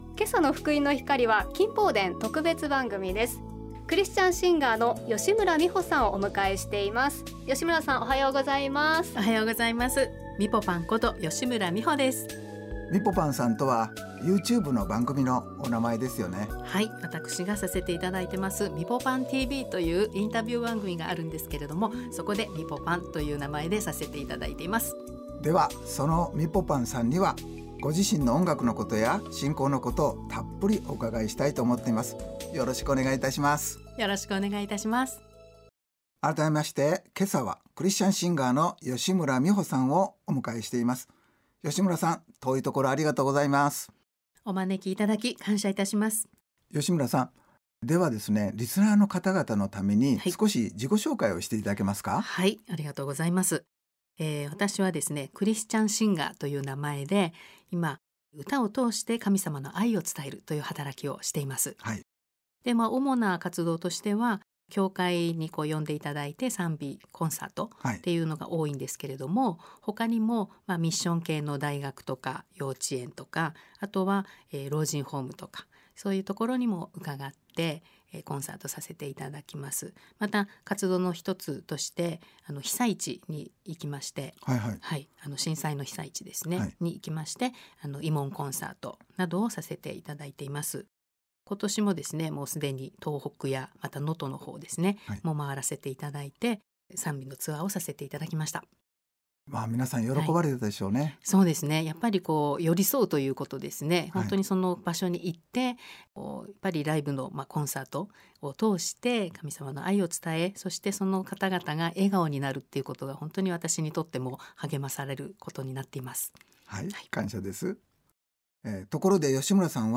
＜近放伝 特別番組＞ 「私の信仰 『小さな光』」